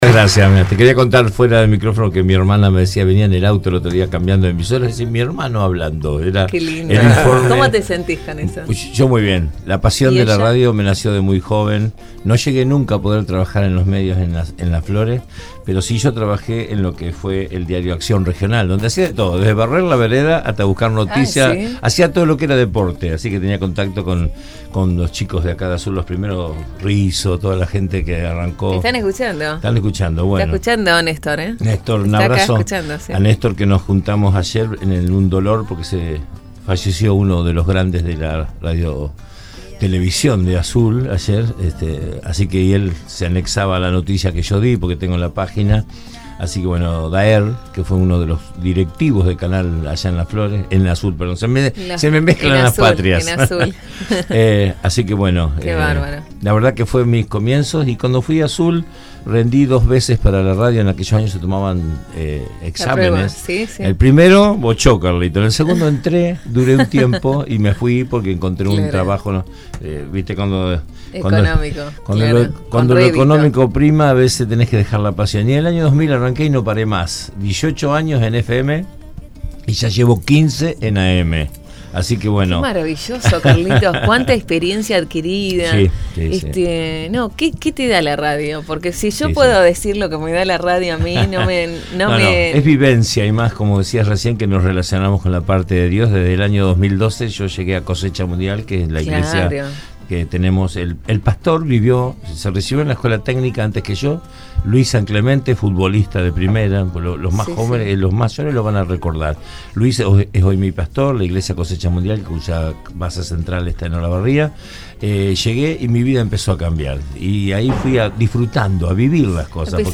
El periodista florense radicado desde hace varios años en Azul visitó este fin de semana los estudios de la 91.5 donde participó del programa «Infomusic Recargado»